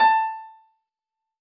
piano-ff-61.wav